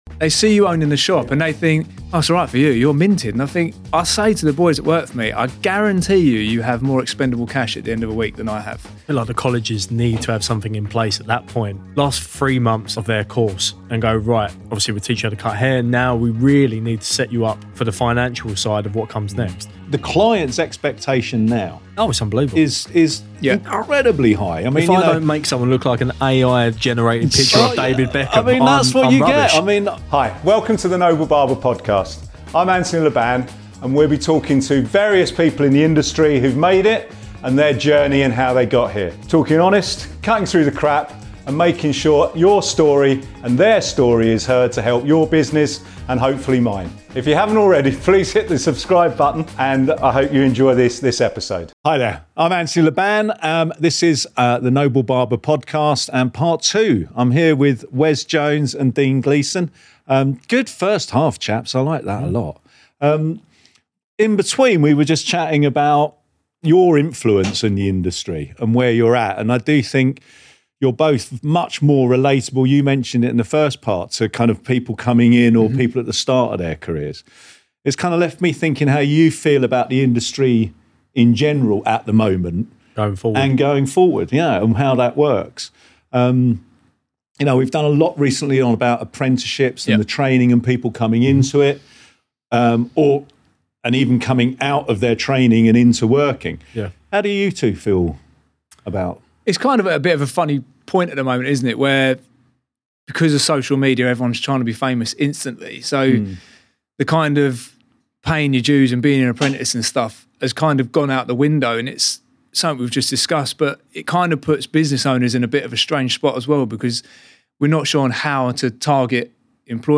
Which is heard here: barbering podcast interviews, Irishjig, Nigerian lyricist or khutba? barbering podcast interviews